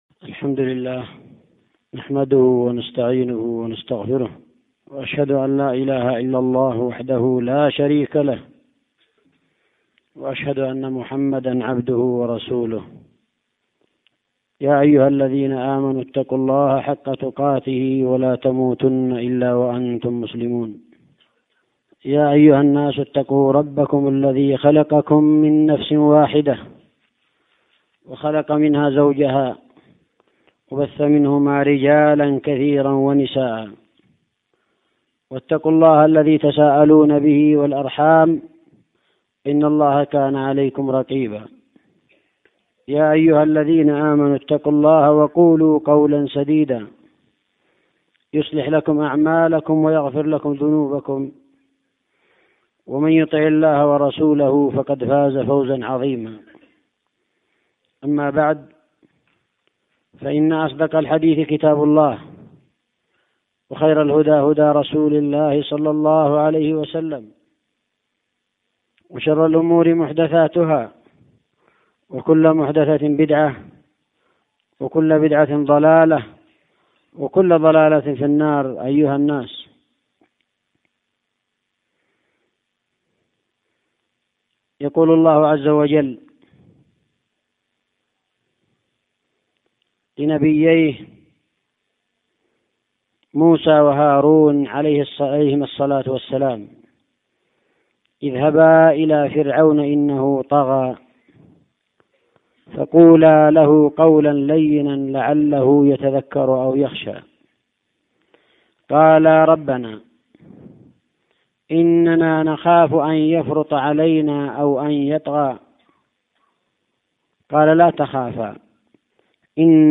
خطبة جمعة بعنوان: (( حسن الظن بالله ))